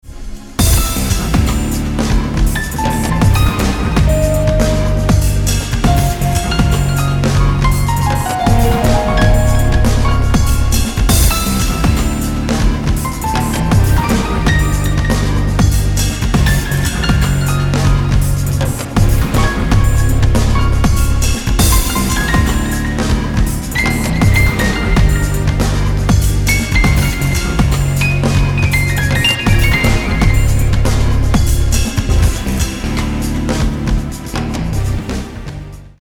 ■All music composition , wards , arrengement & guitar play